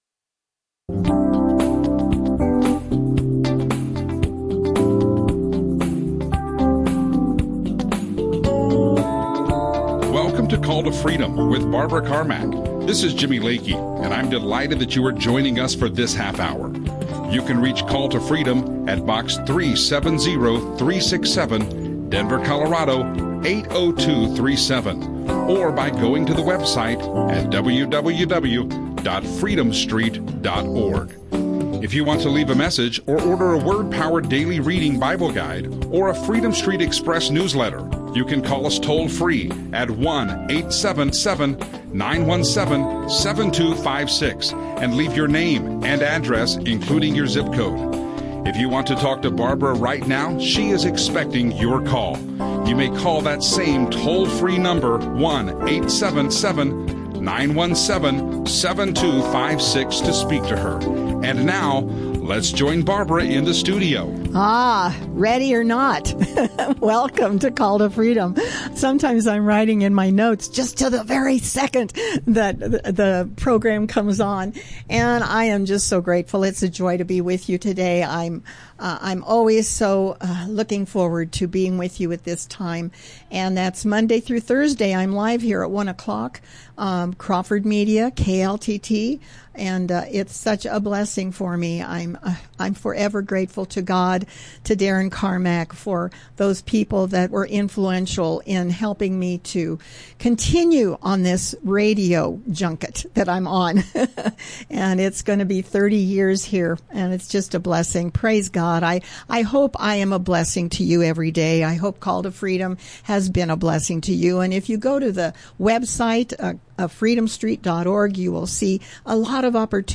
Christian talk
Trump radio show Zion